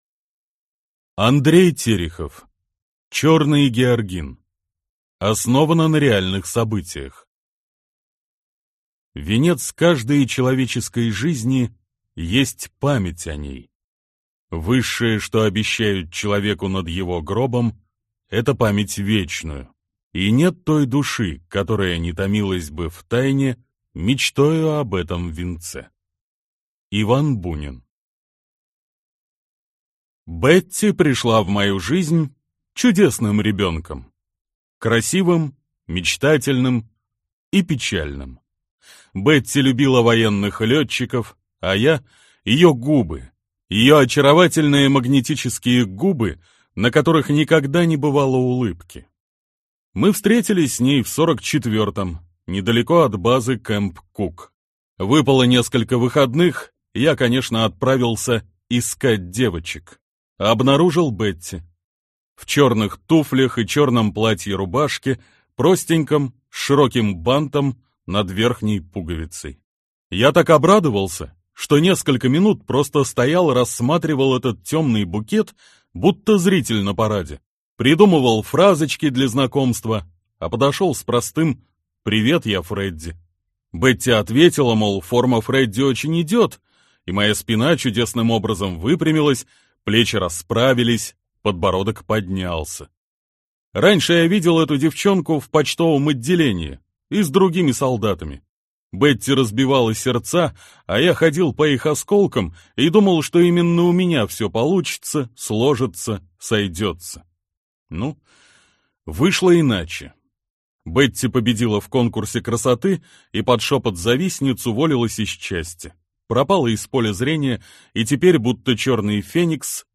Аудиокнига Черный георгин | Библиотека аудиокниг
Прослушать и бесплатно скачать фрагмент аудиокниги